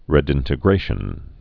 (rĕd-ĭntĭ-grāshən, rĭ-dĭn-)